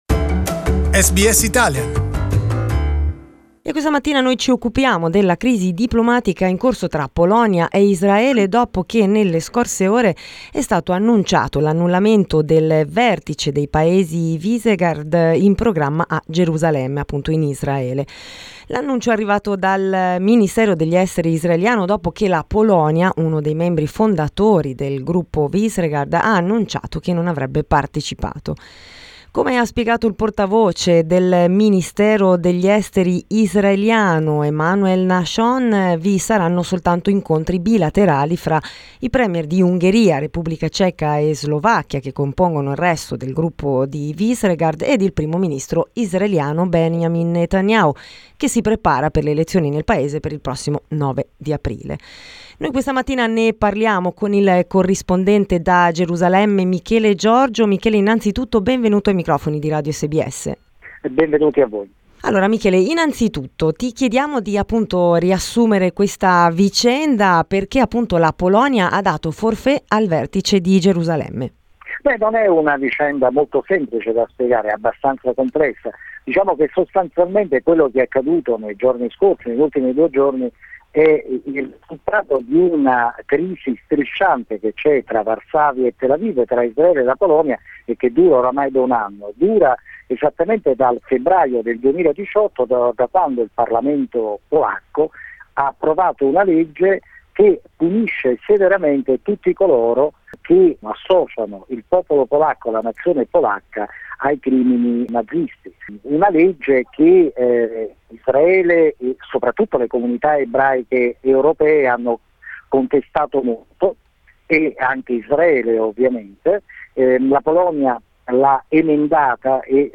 We talked about the crisis with the correspondent from Jerusalem